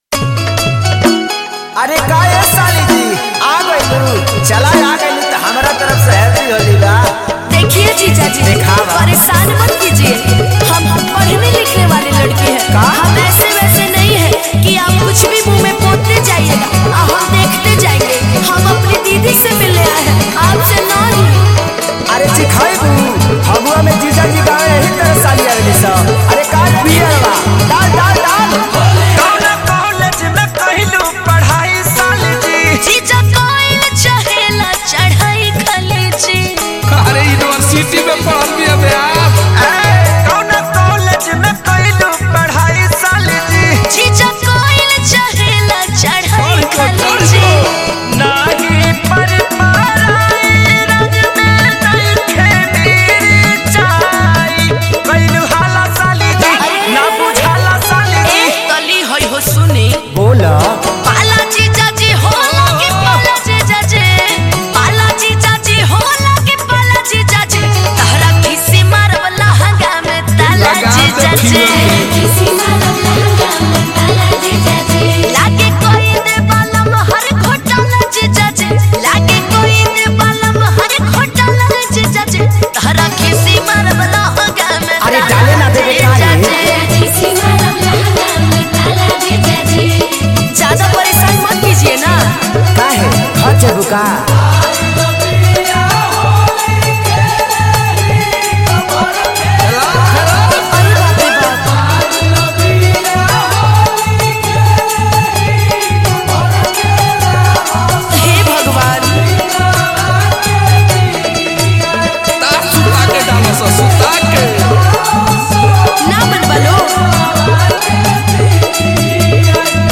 Bhojpuri Mp3 Songs